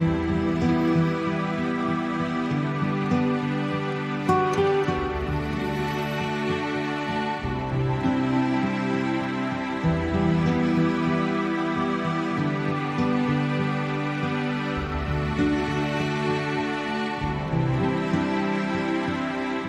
RelaxingMusic.mp3